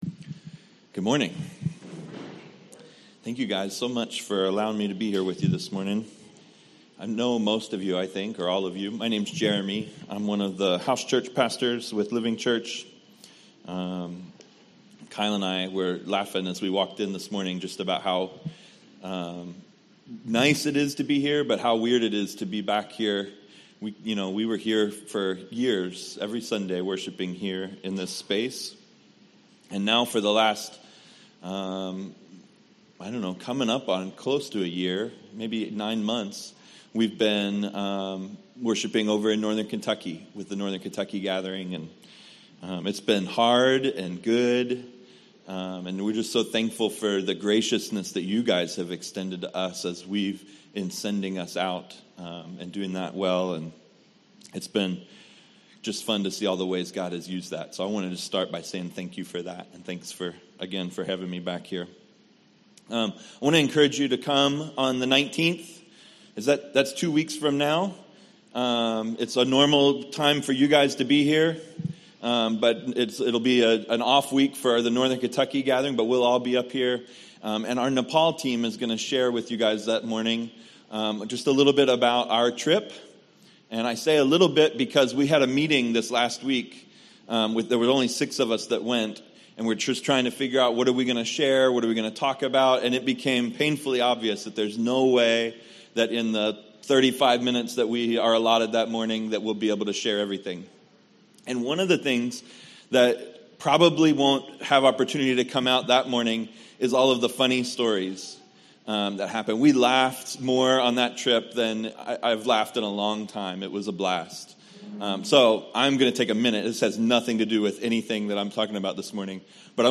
at Cincy Gathering